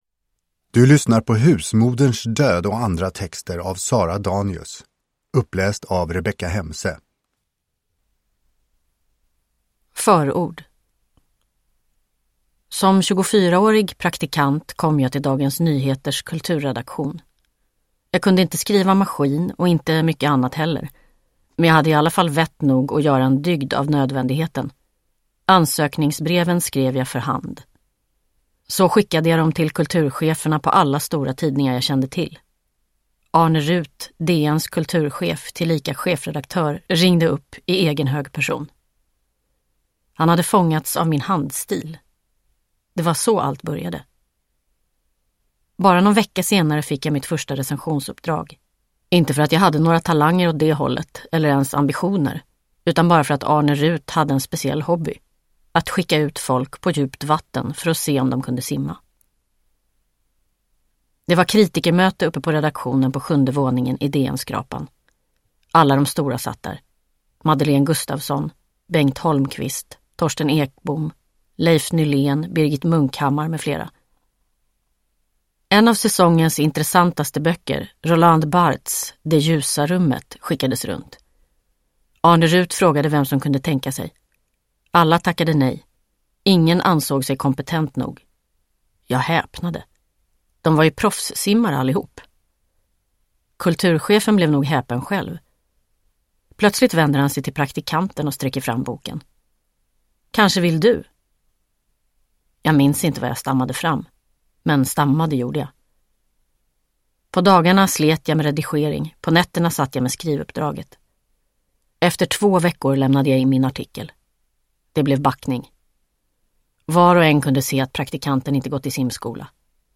Uppläsare: Rebecka Hemse
Ljudbok